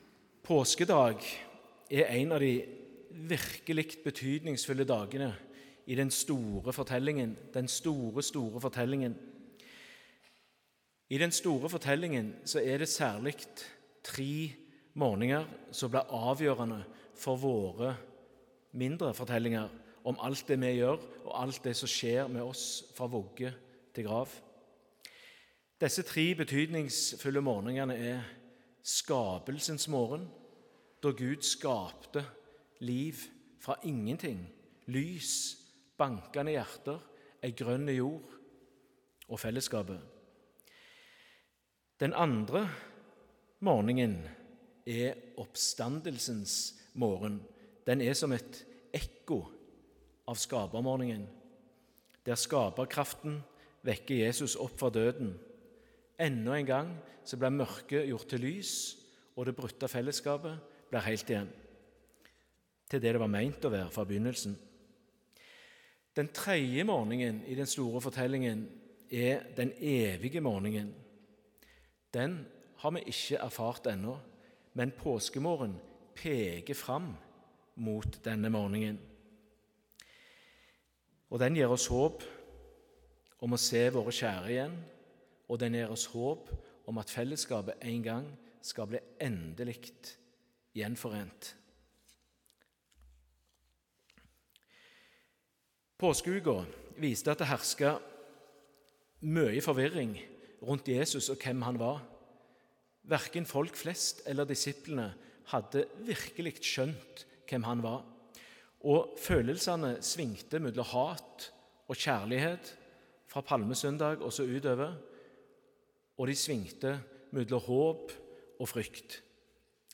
Tekstene Evangelietekst: Joh 20,1–10 Lesetekst 1: 2 Mos 15,1–3 Lesetekst 2: Kol 2,12–15 Utdrag fra talen (Hør hele talen HER ) Forvirring Påskeuken viste at det hersket mye forvirring rundt Jesus.